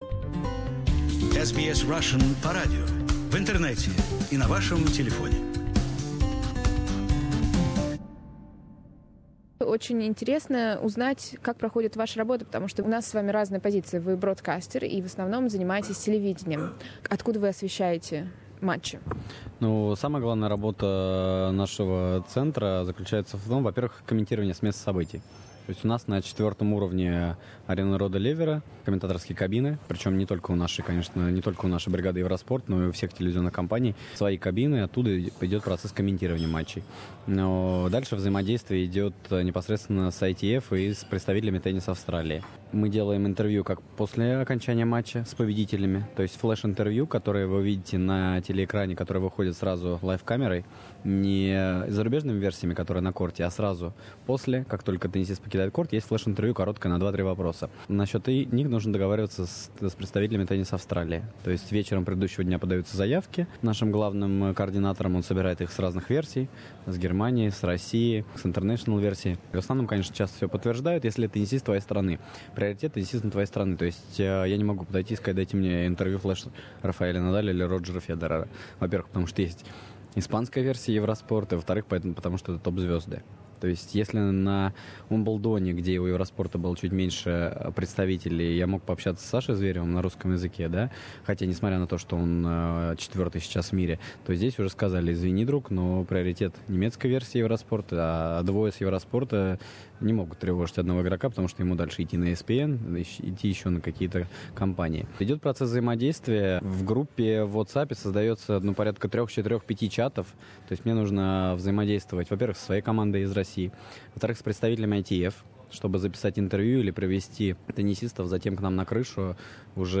This is the first part of the interview.